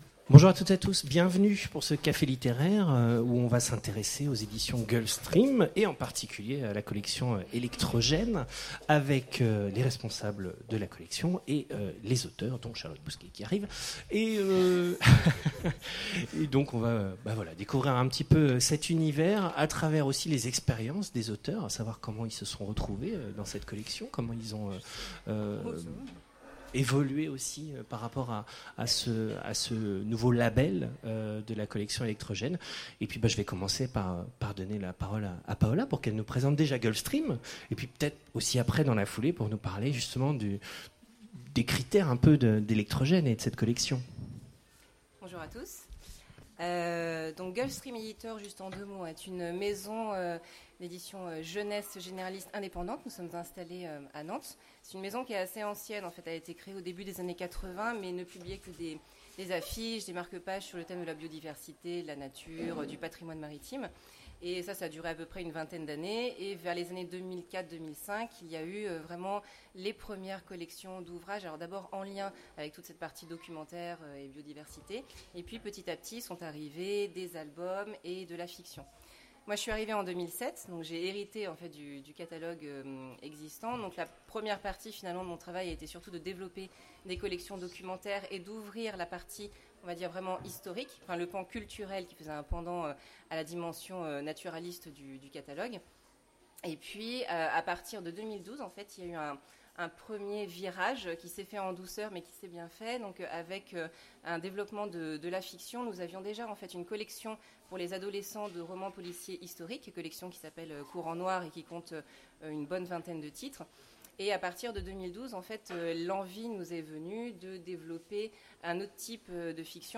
Imaginales 2016 : Conférence Les éditions Gulf Stream, collection électrogène présentent leurs auteurs
Conférence